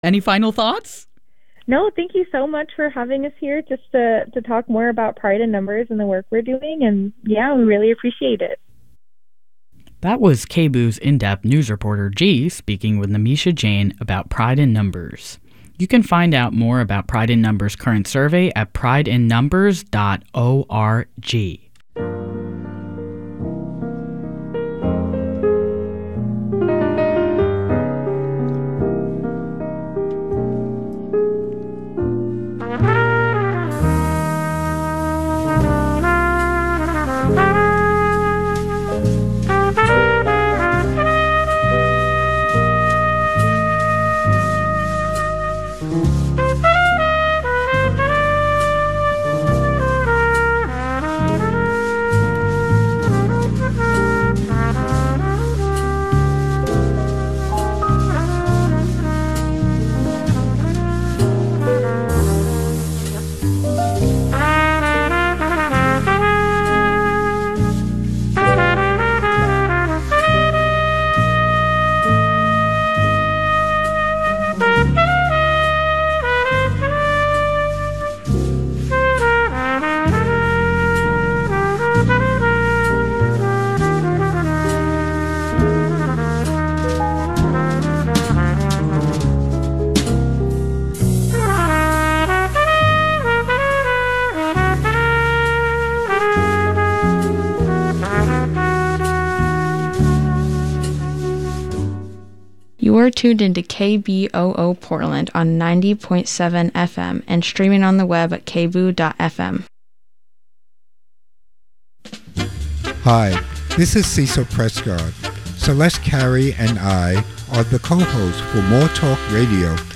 PDX Progressive Talk Radio on 04/01/26